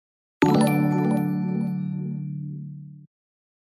Звуки для подписки
Звук подписки на стрим